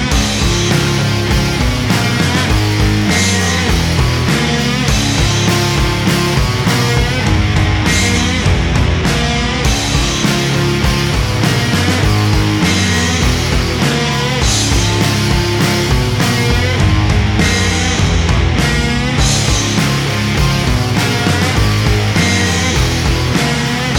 Indie / Alternative